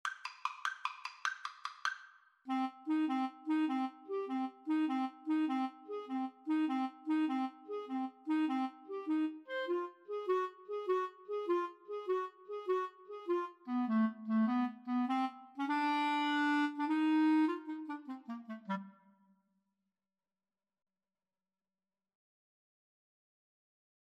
3/8 (View more 3/8 Music)
Classical (View more Classical Clarinet Duet Music)